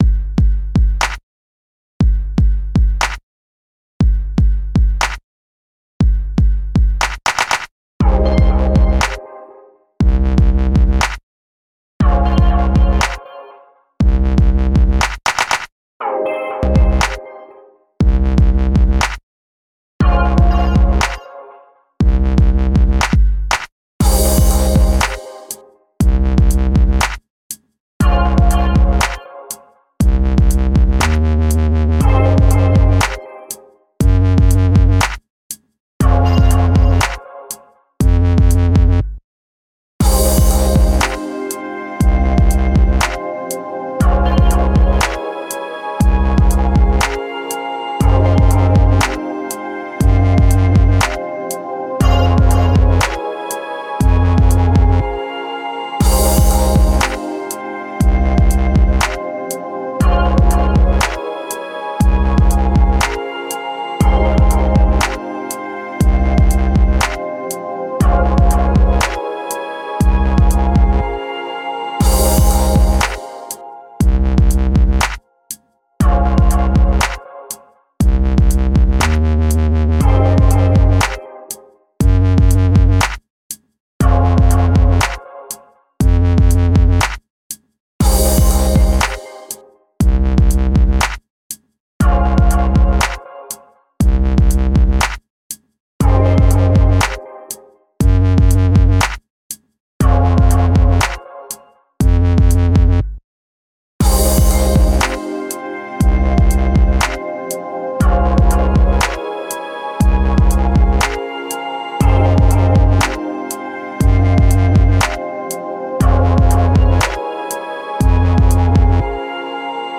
A hip hop song with elements of dubstep.